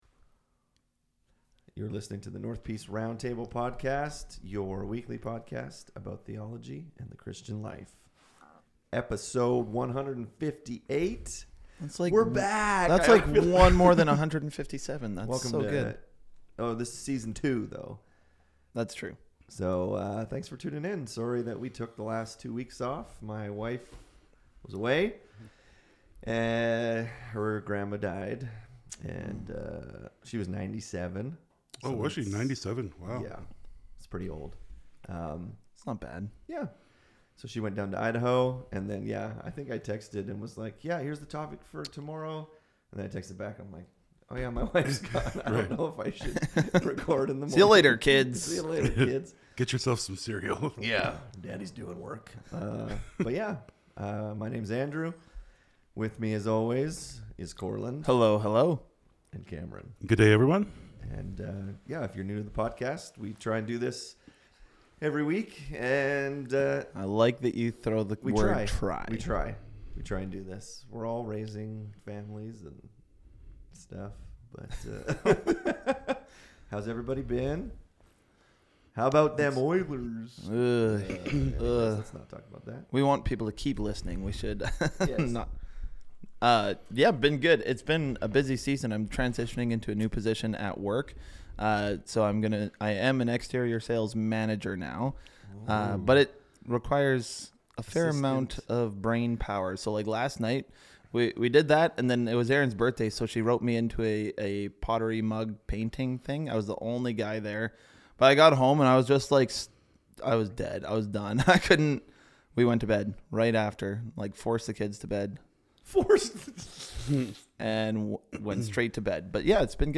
In this episode the guys talk about apostles and prophets.